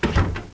door1.mp3